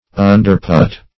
Underput \Un`der*put"\